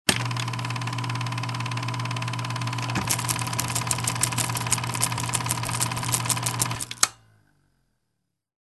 Скрип и гул старого кинопроектора